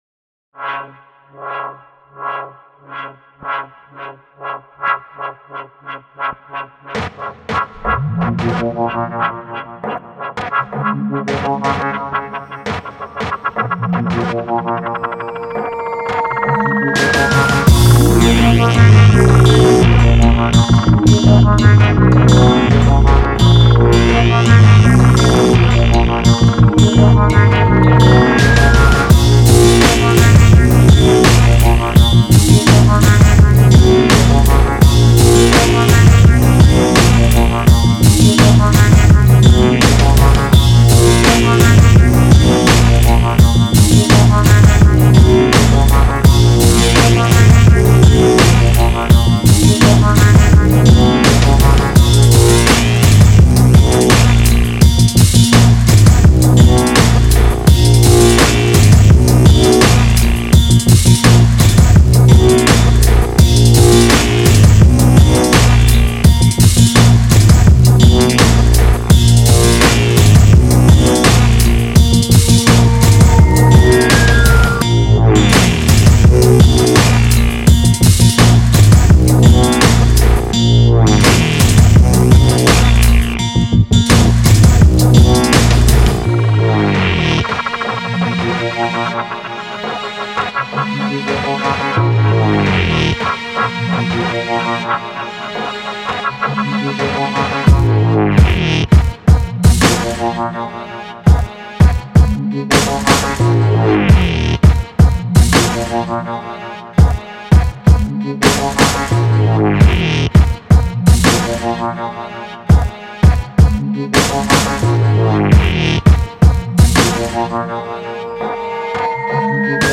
Basically raw, hard hitting tunes